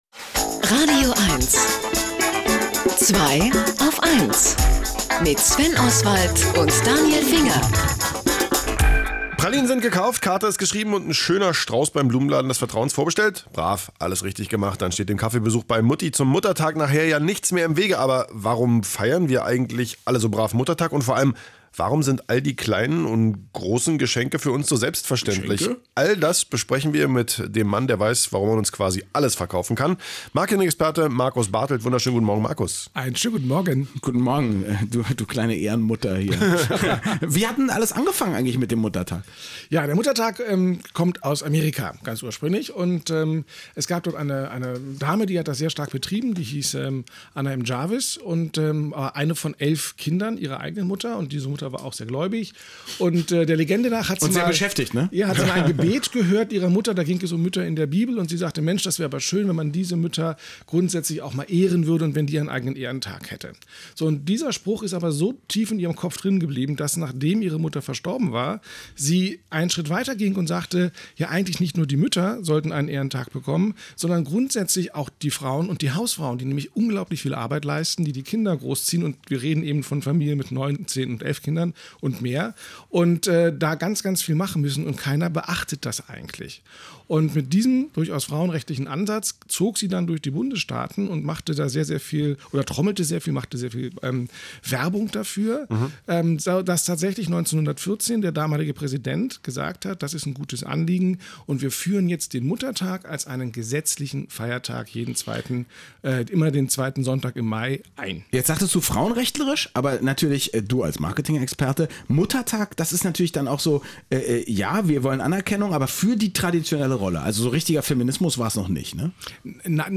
Und deshalb war ich wieder einmal zu Gast im radioeins-Studio. Nachzuhören ist das Interview hier: